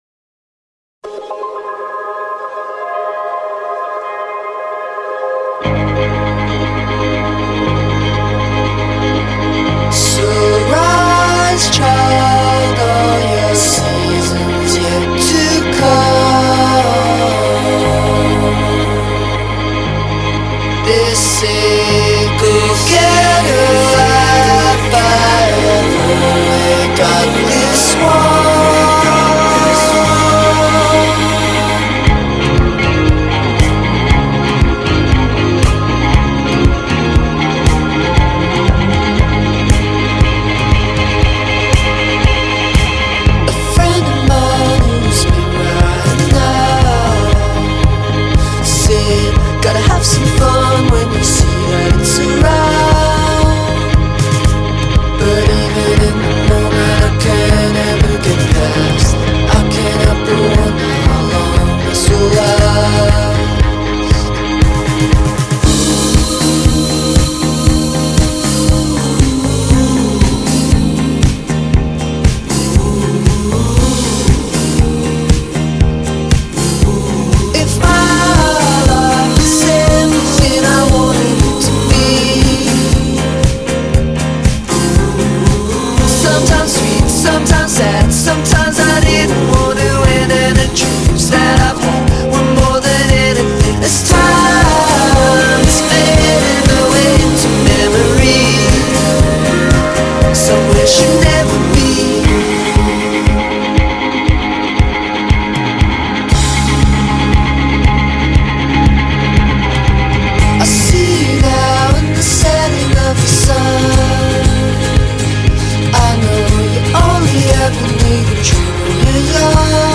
and the song drips with passion.